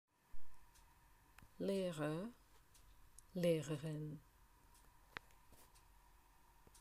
Klikom na strelicu čućete izgovor svake reči koja označava profesiju.